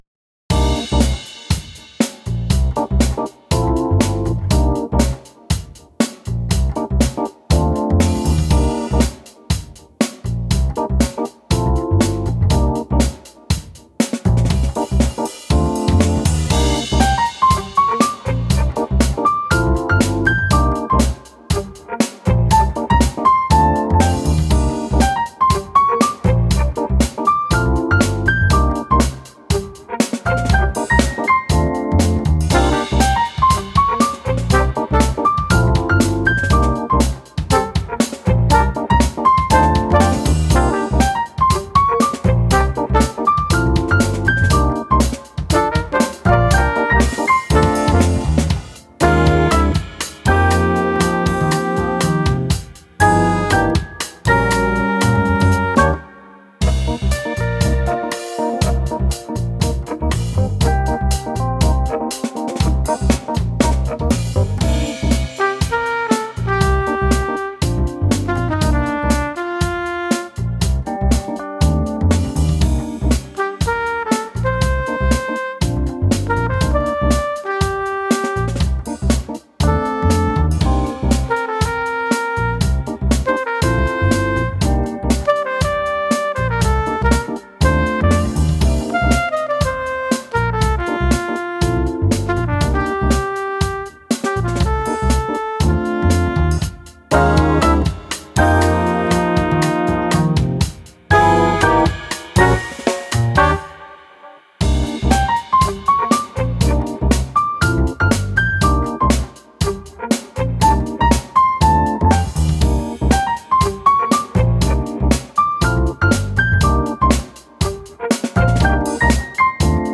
Инструментальчик (Jazzy)